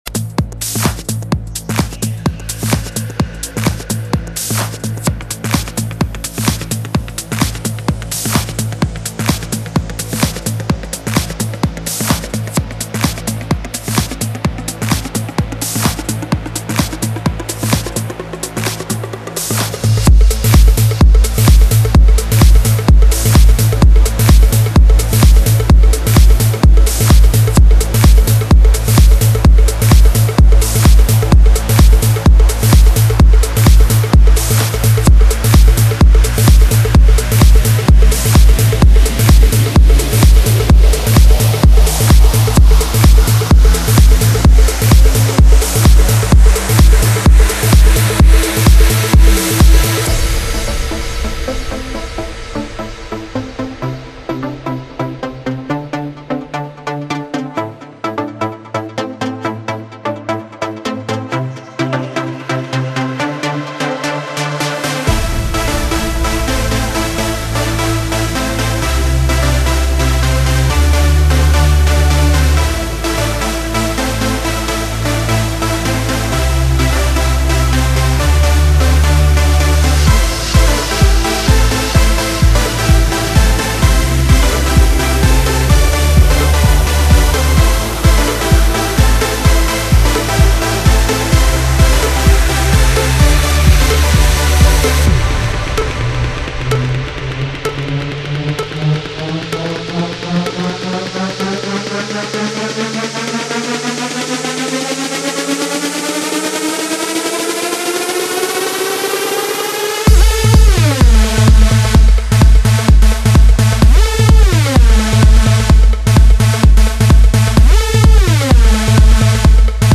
Стиль: Progressive House
Позитив | Энергия | Чувство | Ритм | Стиль | Движение